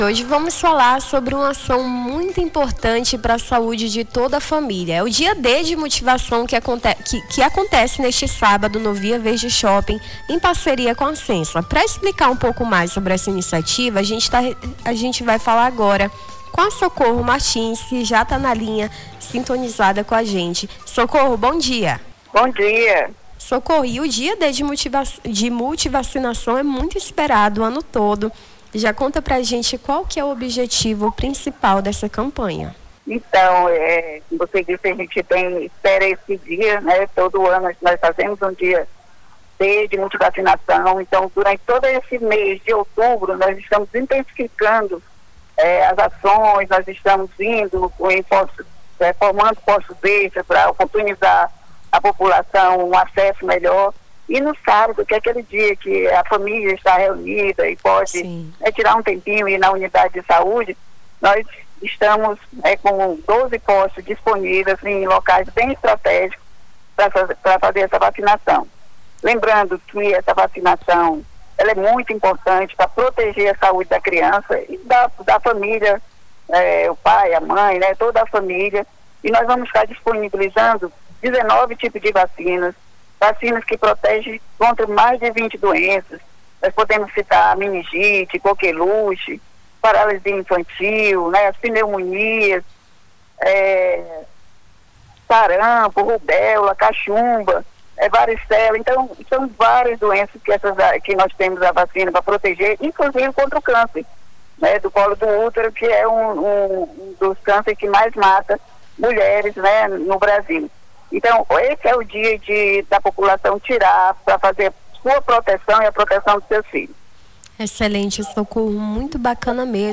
ENTREVISTA DIA D DE VACINAÇÃO